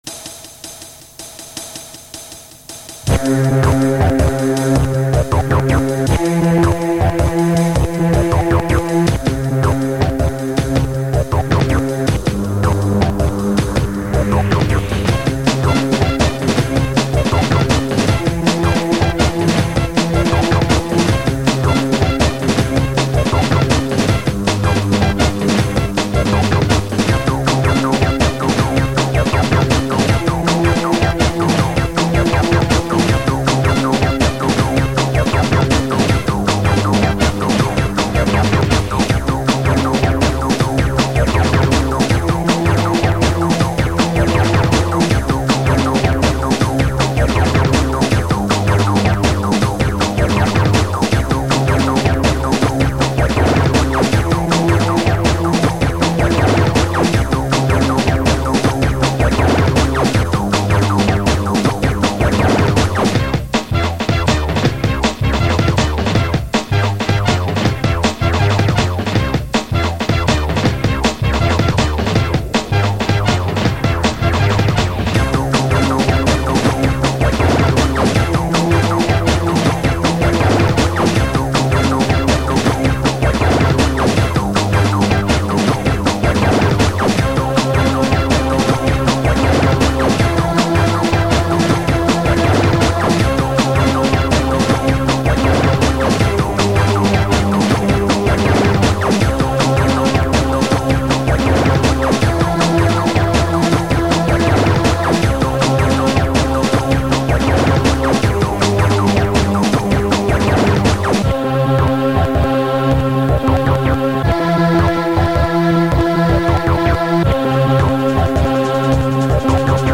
I made this when I was in my teens, using a Playstation. I just found the tape with it on...